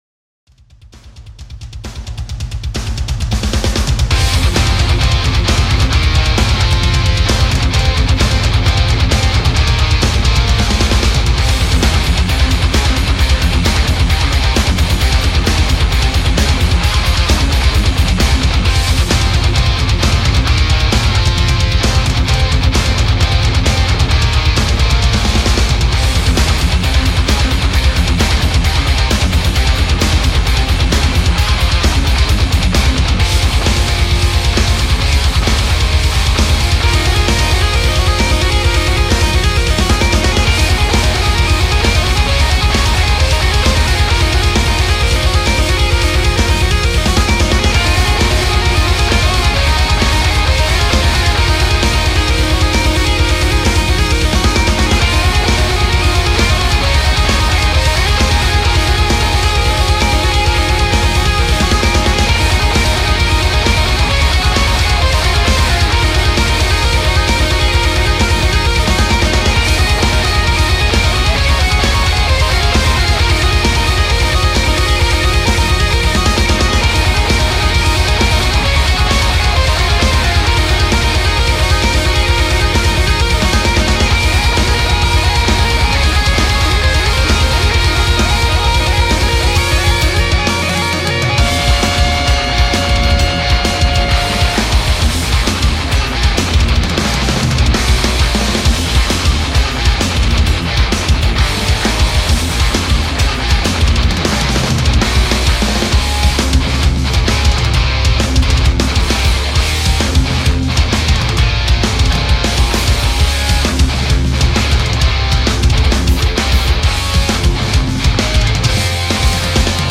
Derpy's super hero heavy metal theme song, awww yeeeeah.